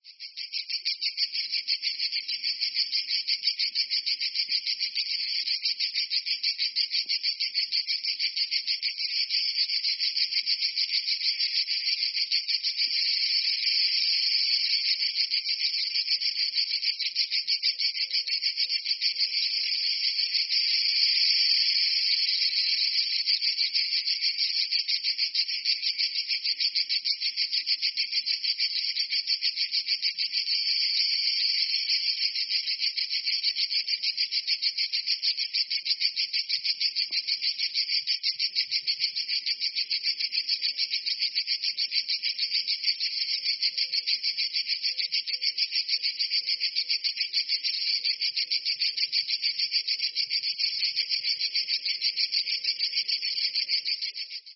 cicadas.415c42.mp3